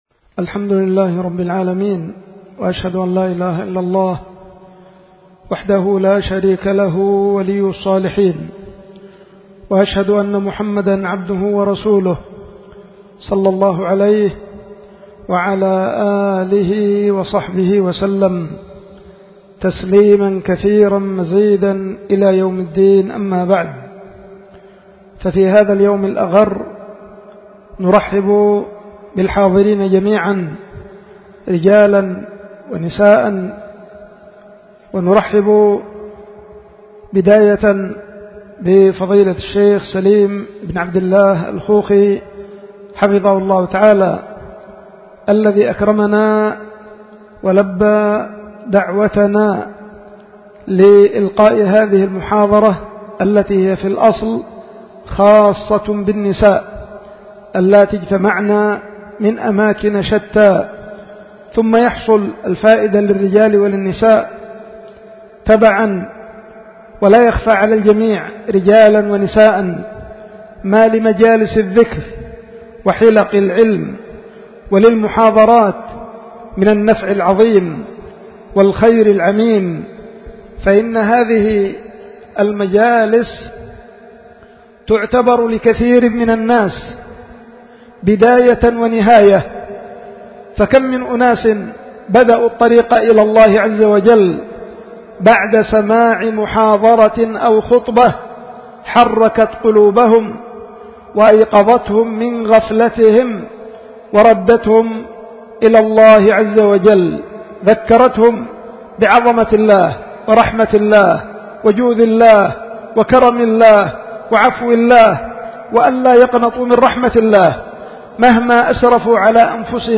محاضرة للنساء